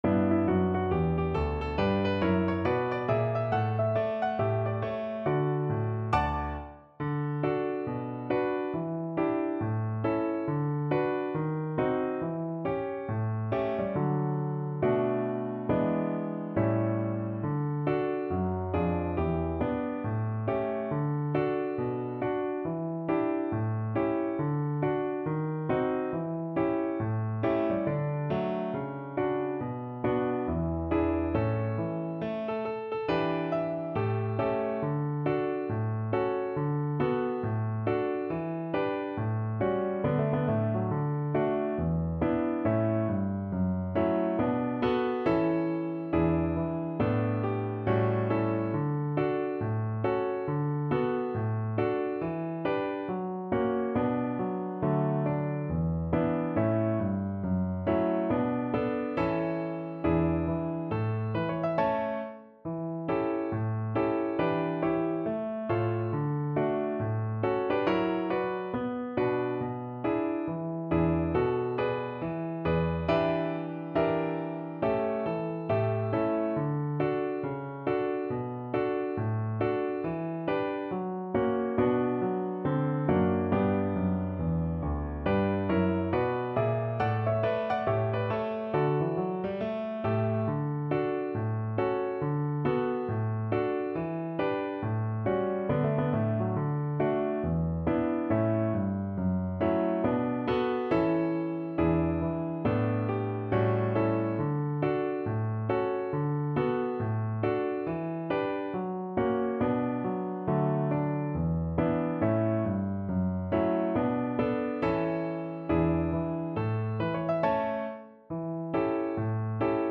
With a swing =c.69